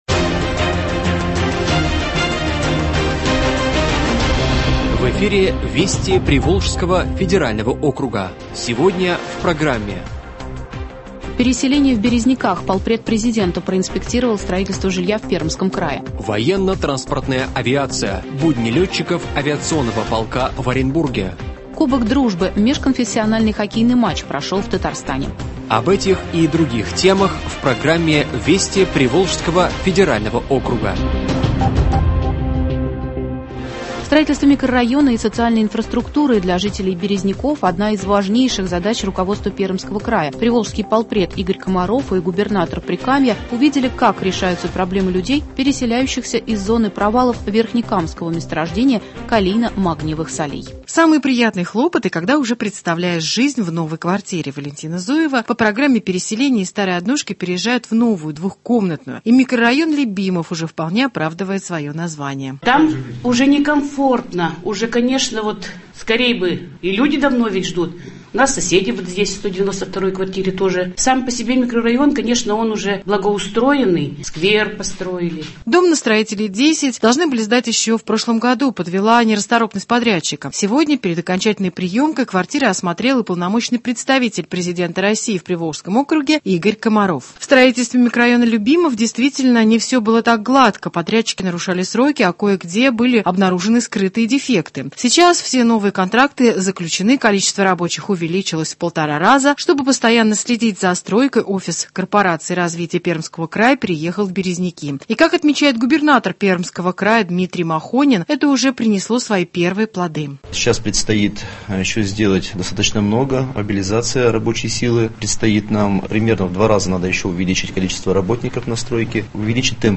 Радиообзор событий регионов ПФО.